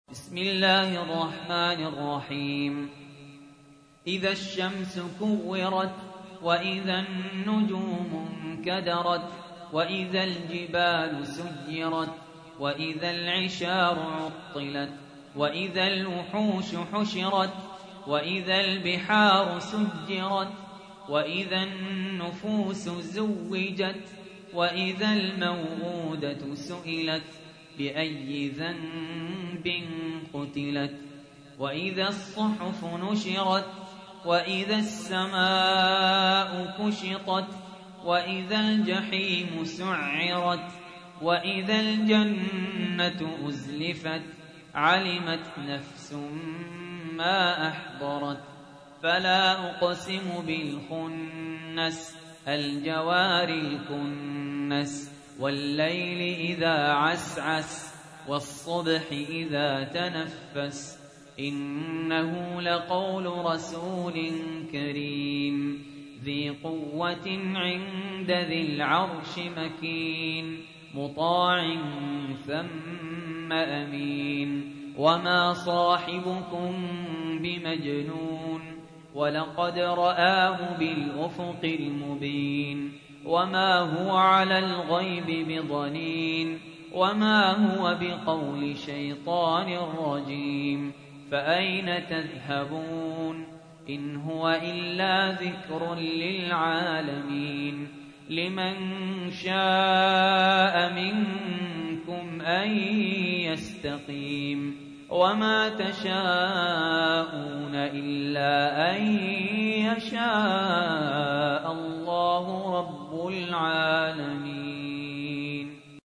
تحميل : 81. سورة التكوير / القارئ سهل ياسين / القرآن الكريم / موقع يا حسين